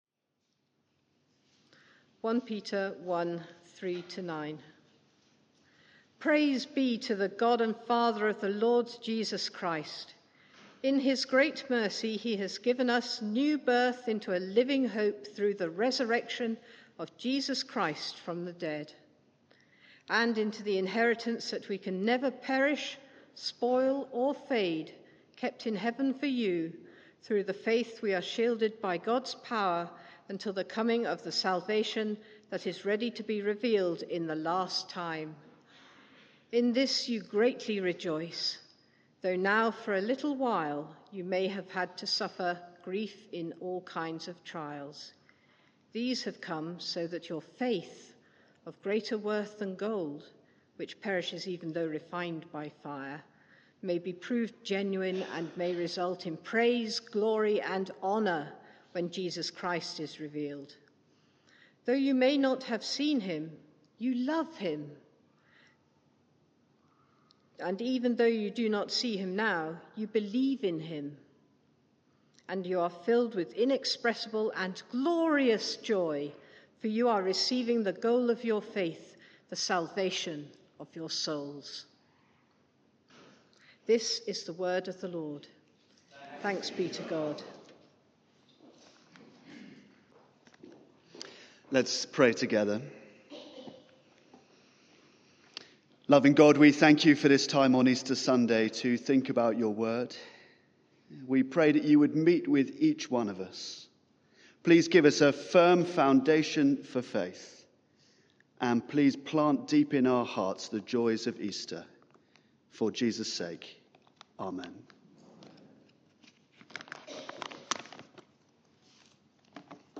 Media for 9:15am Service on Sun 17th Apr 2022 09:15 Speaker
Sermon